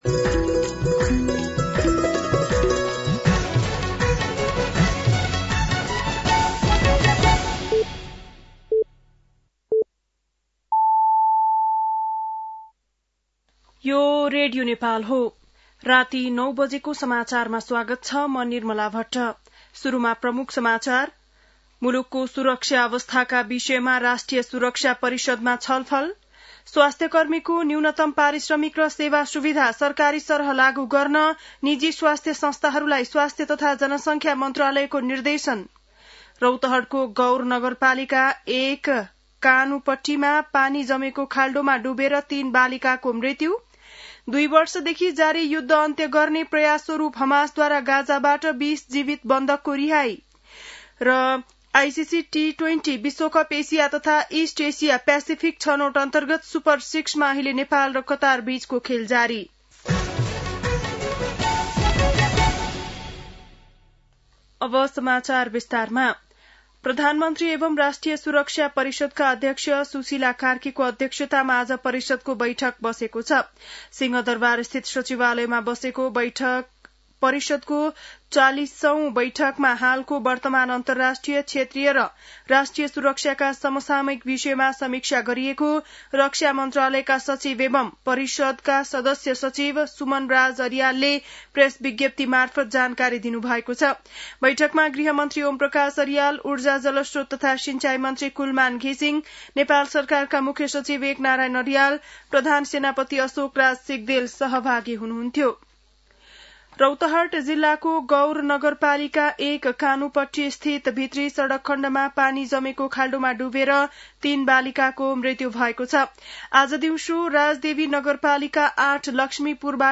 बेलुकी ९ बजेको नेपाली समाचार : २७ असोज , २०८२
9-PM-Nepali-NEWS-6-27.mp3